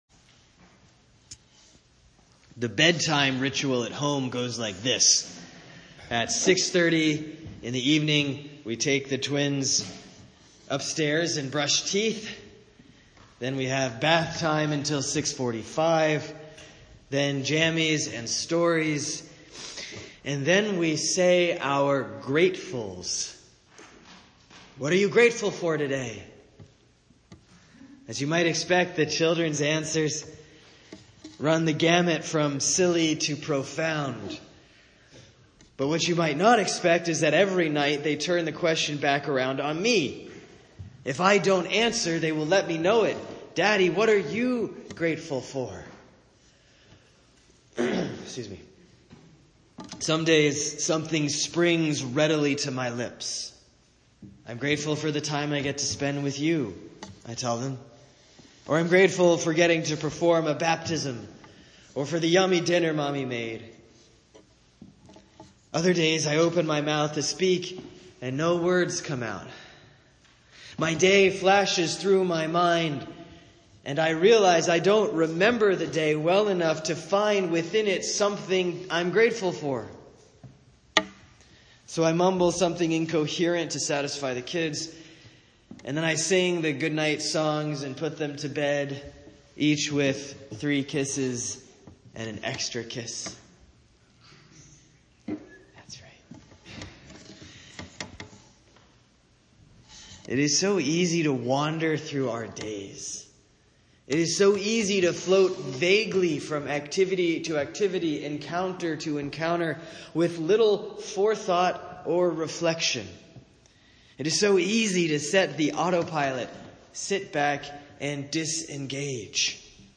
Homily for Thanksgiving Day, November 23, 2017